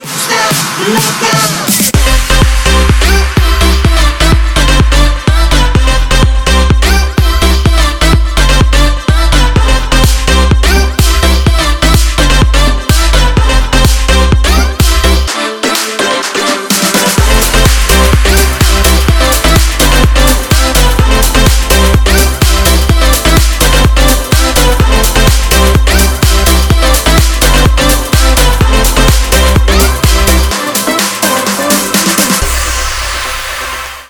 громкие
заводные
EDM
future house
бодрые
Заводная музычка от двух замечательных Dj